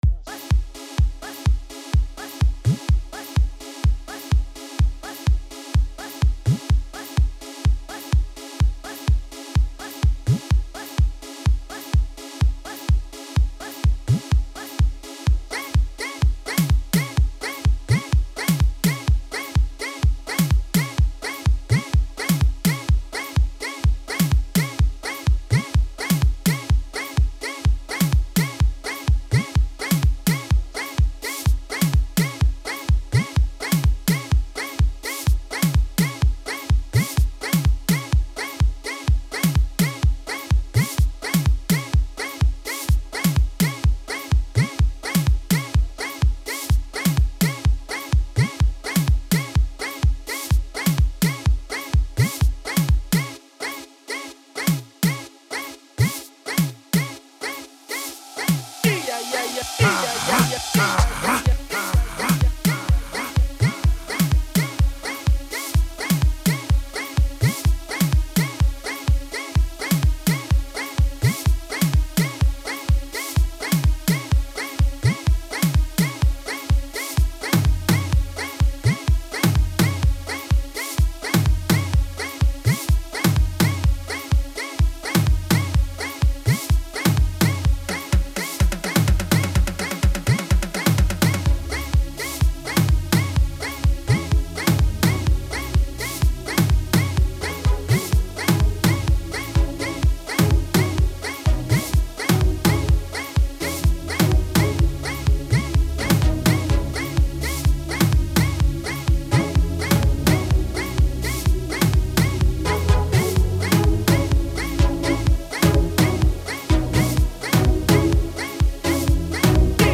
Genre : Gqom